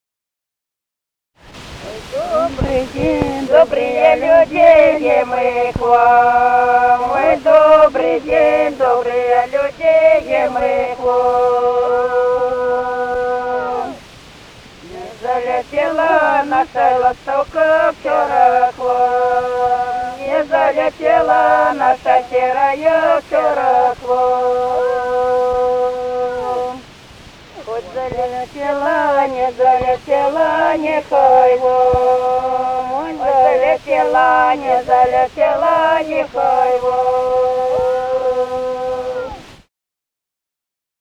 Записали участники экспедиции